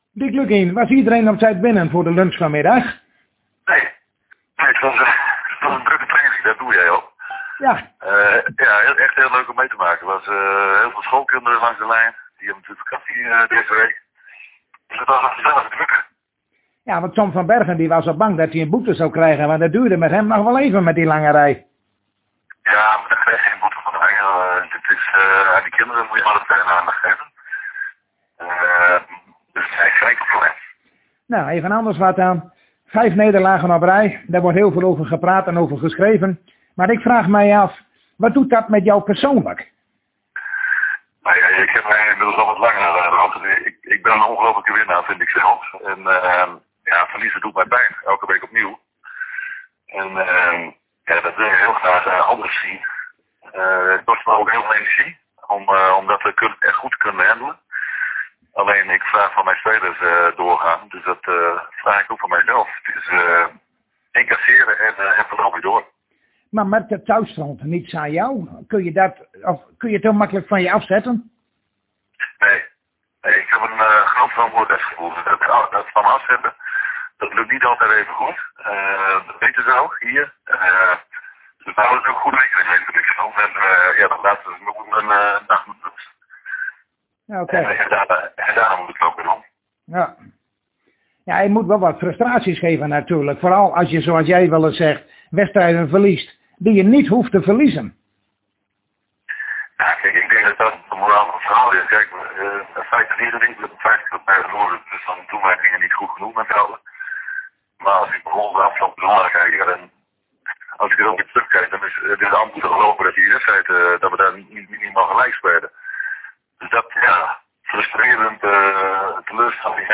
Zojuist spraken wij weer met hoofdtrainer Dick Lukkien van FC Groningen over de wedstrijd van zondag a.s. in en tegen Volendam.